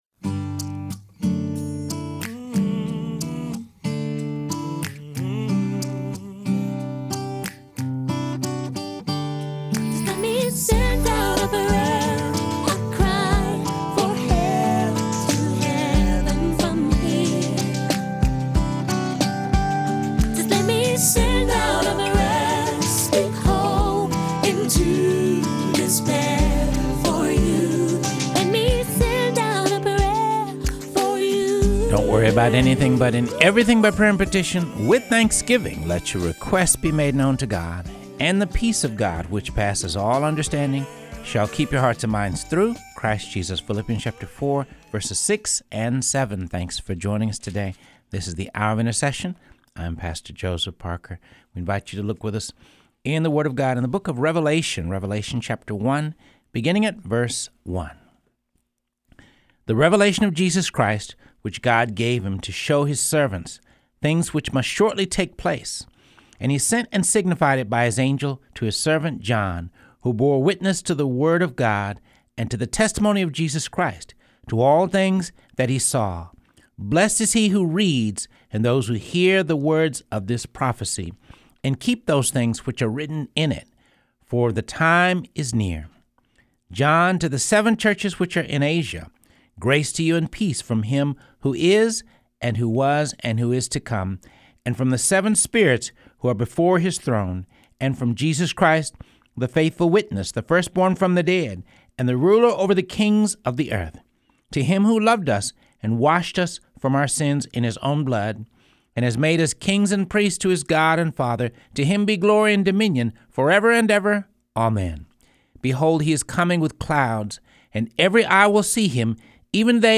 Reading through the Word of God | Episode 65